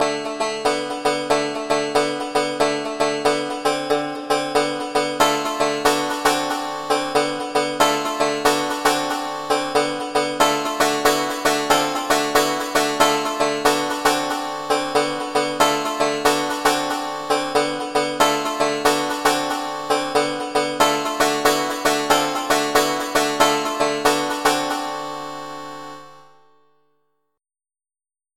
or on different channels with the same patch (banjo) but panned left and right with